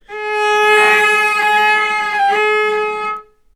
vc_sp-G#4-ff.AIF